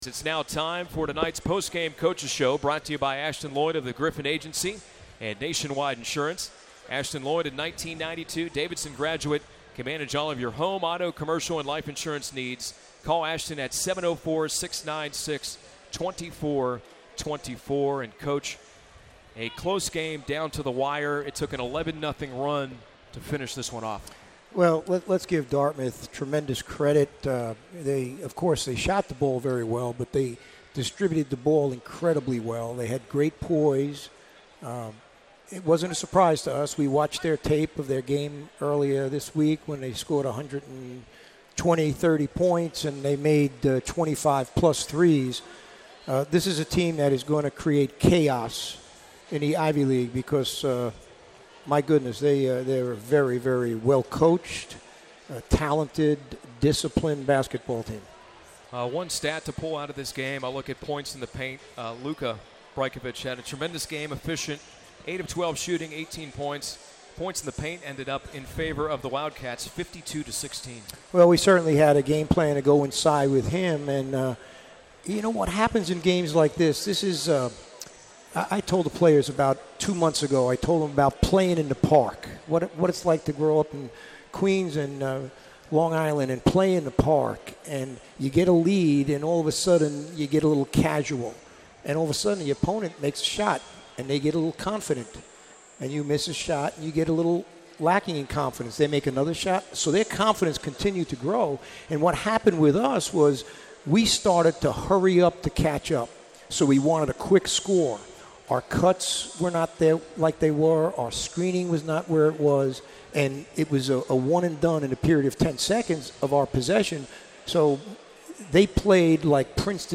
DartmouthPostgame.mp3